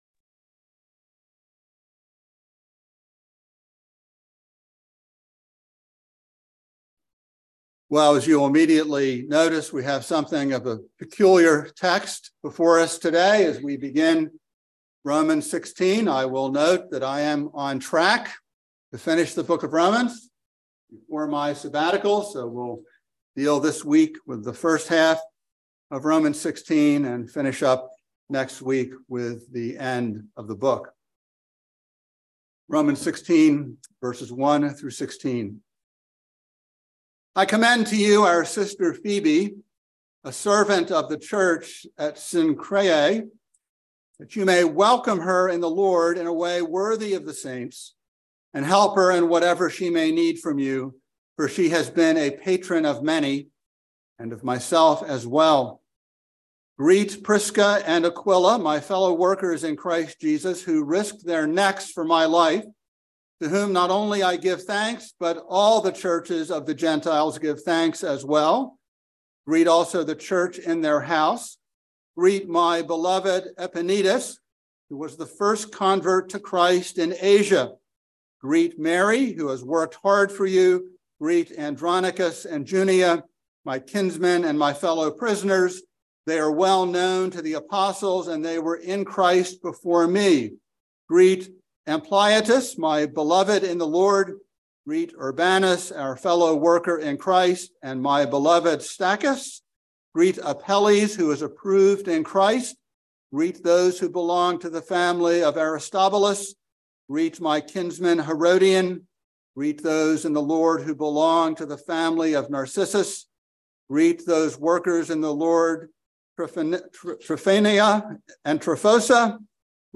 by Trinity Presbyterian Church | May 16, 2023 | Sermon